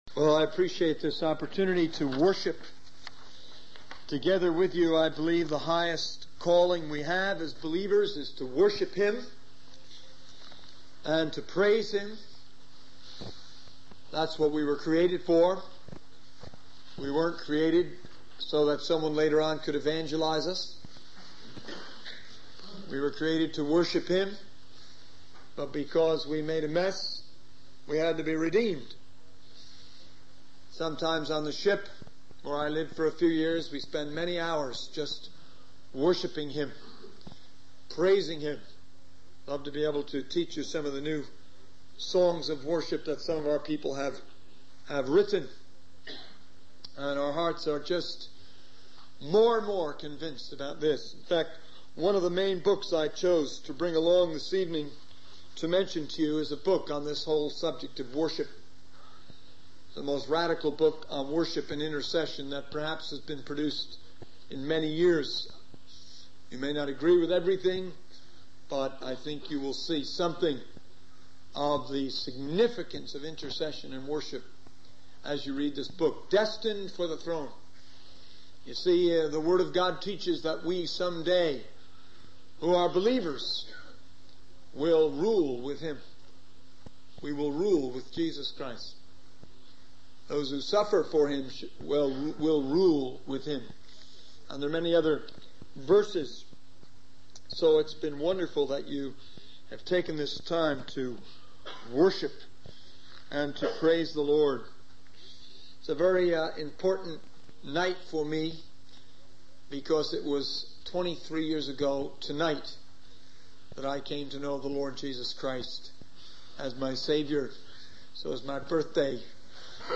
In this sermon, the speaker emphasizes the importance of getting into God's Word and challenges the audience to memorize it.